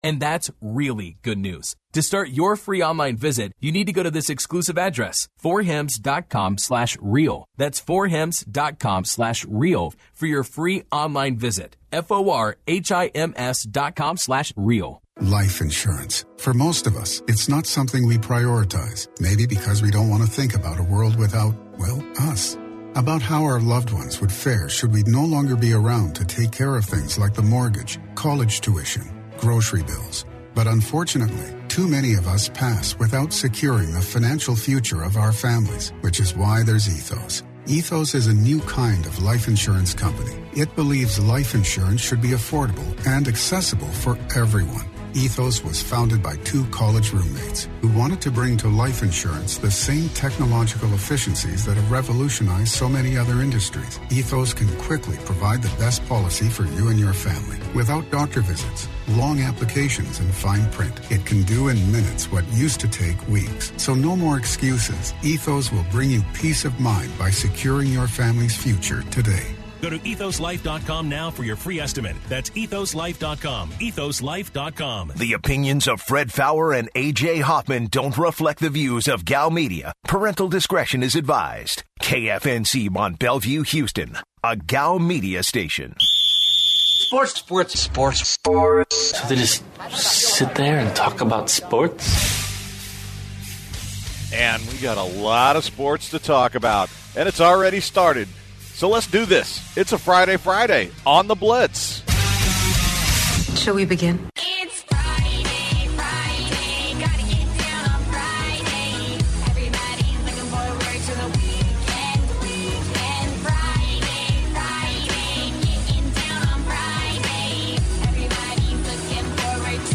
The guys today are hosting the show from Los Angeles and start off the hour talking about their experiences today. They also talk about the NFL and the football matchups coming up on Sunday.